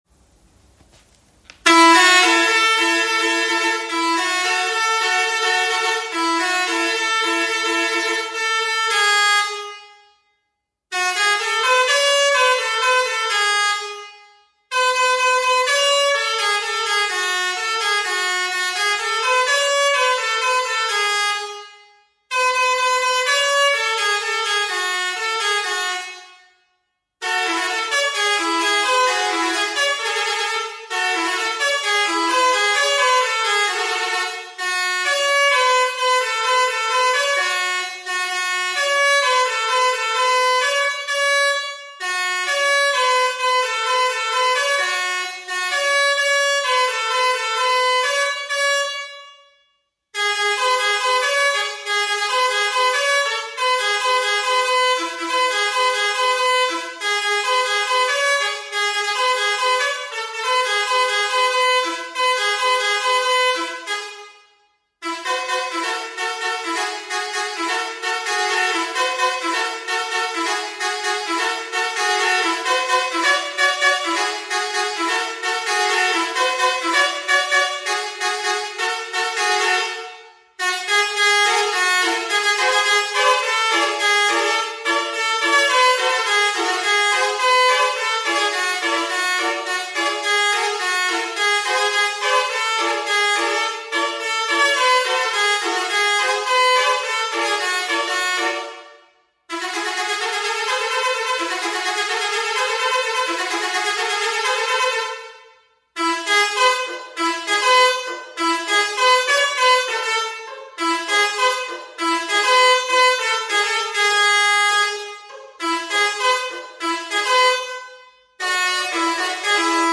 • Puissance de 130 décibels et évasement de 10,5 cm.
• Fréquence de 200 à 400 Hertz.
Tous les sons indiqués sont seulement inspirés des mélodies et artistes cités.
Ce Klaxon diffuse un son puissant pour attirer l'attention.